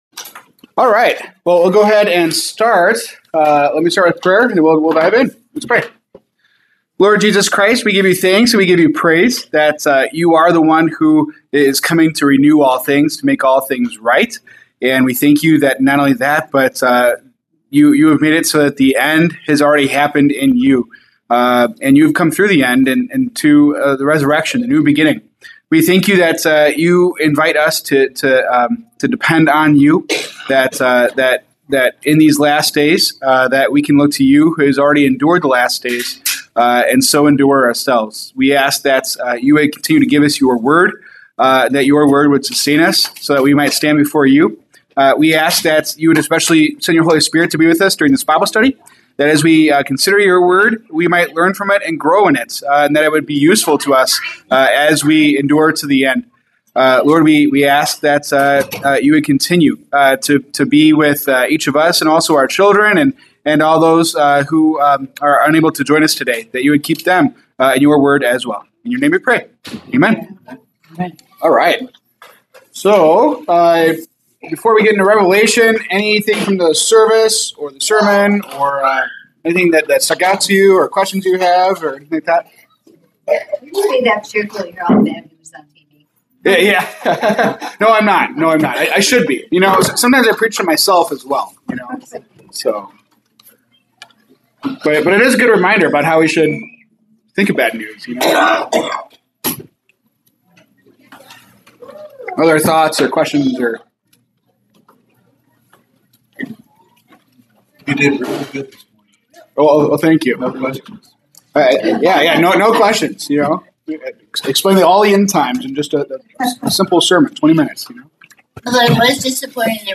November 16, 2025 Bible Study
Discussion on Revelation 3:7-13.